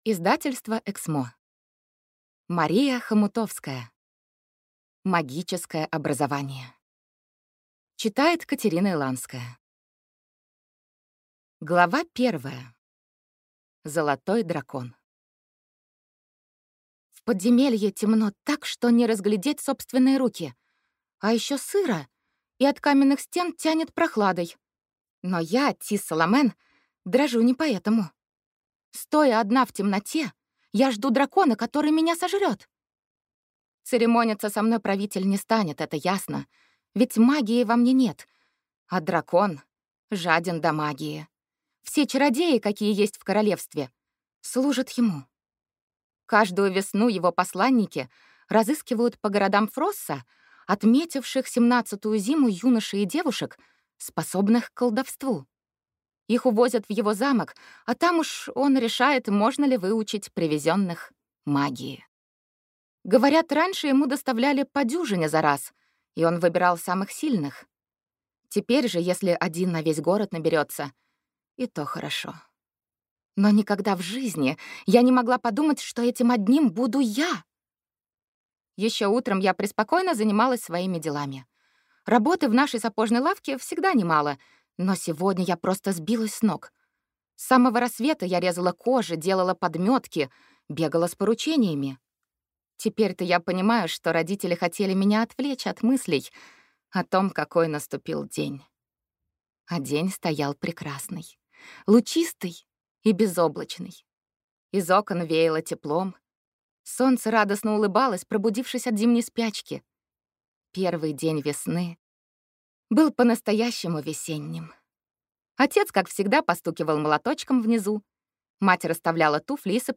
Аудиокнига Магическое образование | Библиотека аудиокниг